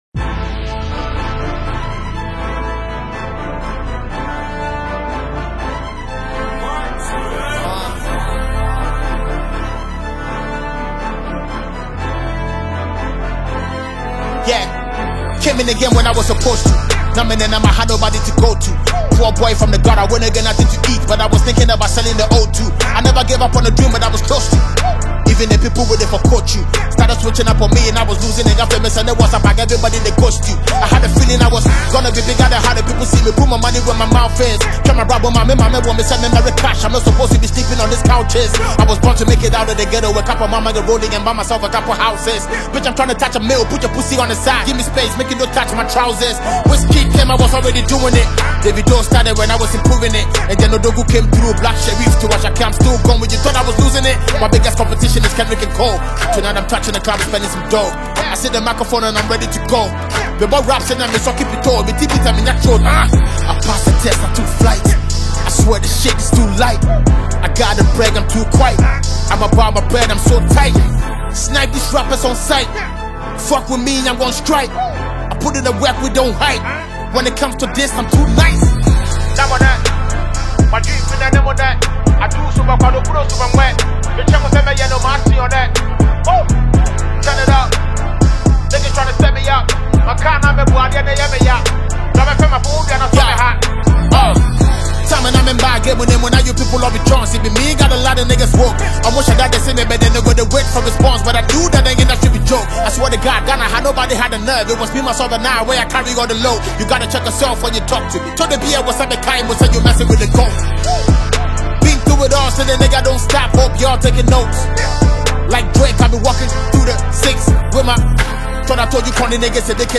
Legendary rapper
rap music